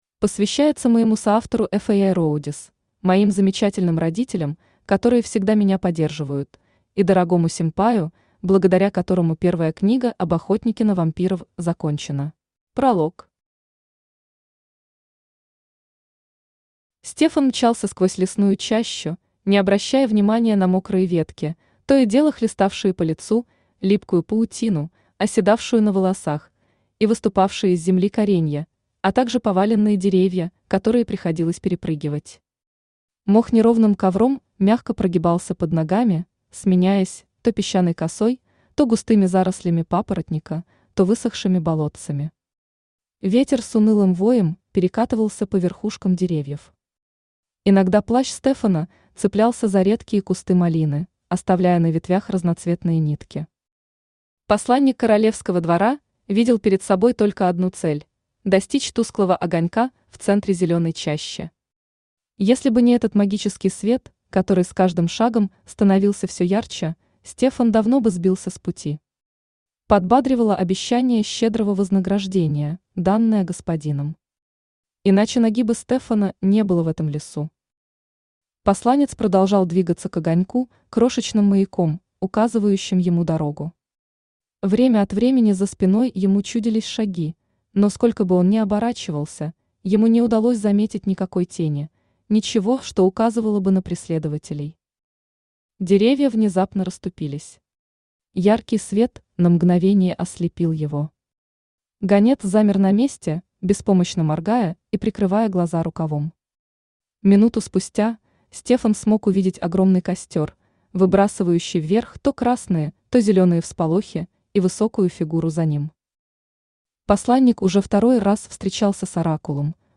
Аудиокнига Последний. Дети вампира | Библиотека аудиокниг